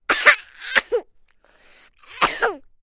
Звук детского кашля